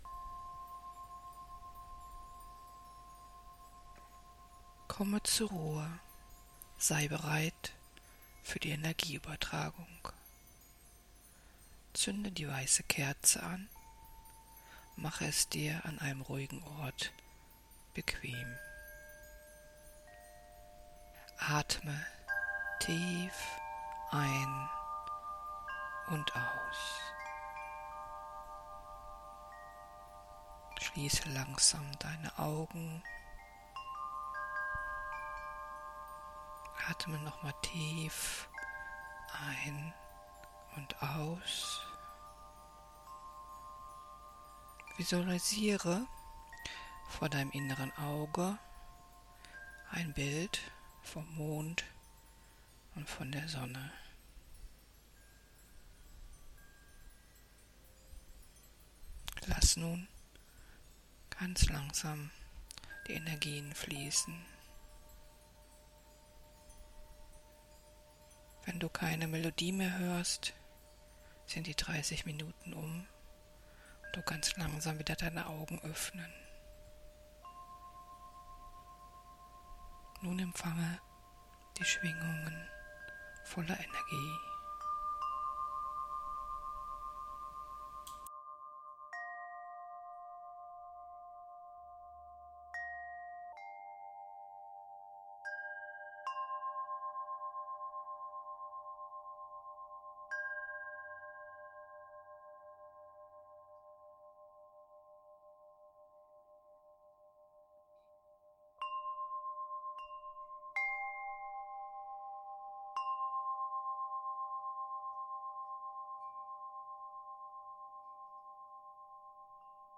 Meditation positive Schwingungen -